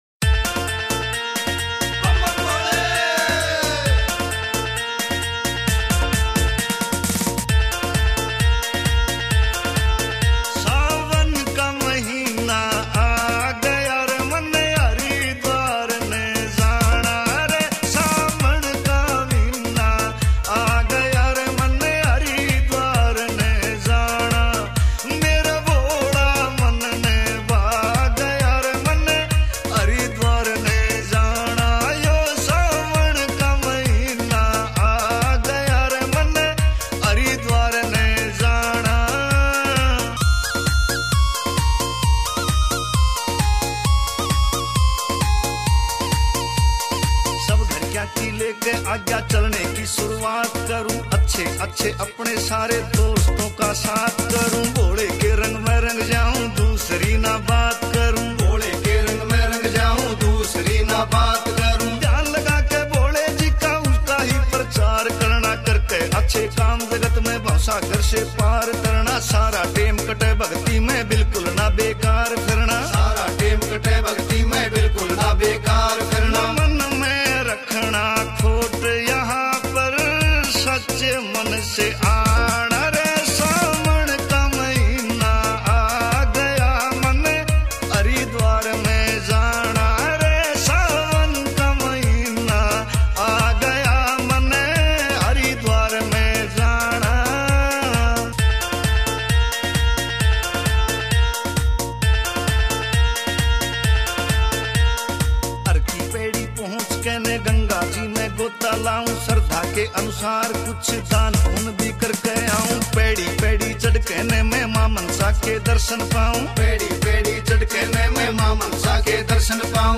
Bhakti Songs
» Haryanvi Songs